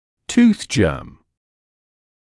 [tuːθ ʤɜːm][туːс джёːм]зубной зачаток